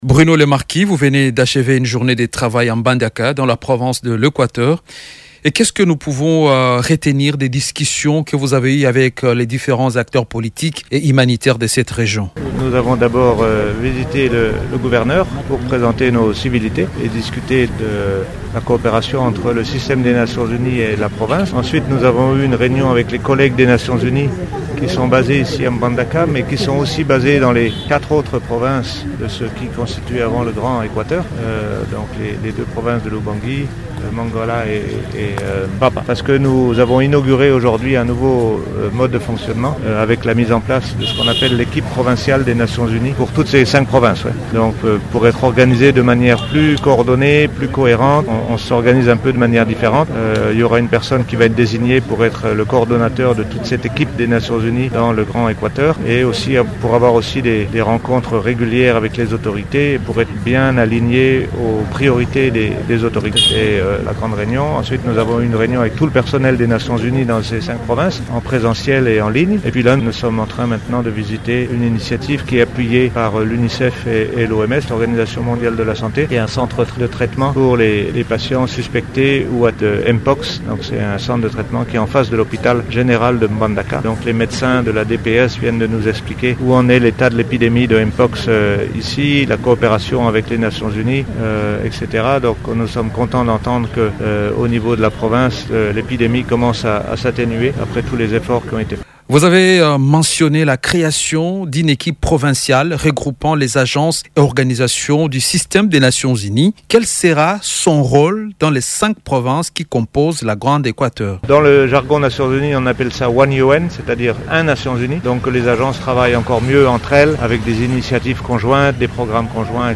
Le Coordonnateur résident du système des Nations unies en RDC, Bruno Lemarquis, a accordé une interview à Radio Okapi au terme de sa visite à Mbandaka (Equateur), lundi 10 mars.